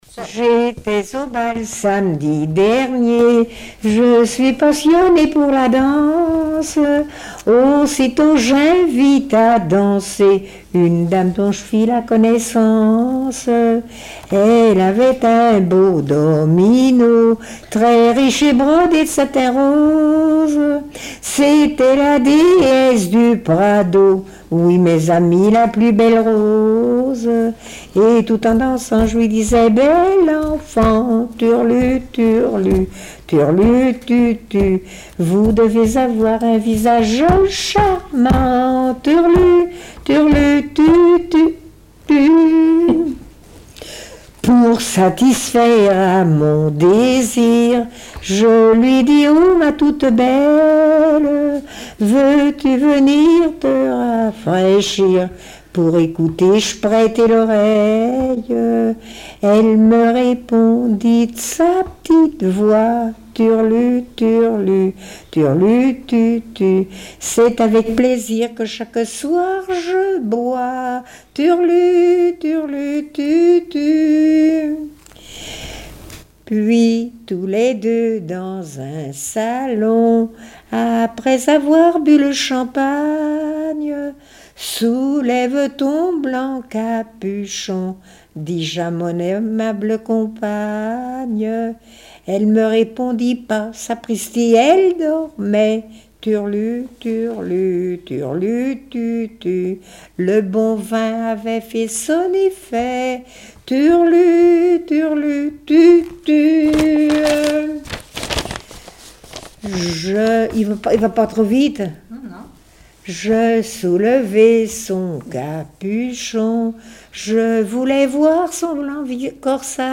Genre strophique
Chansons et témoignages
Pièce musicale inédite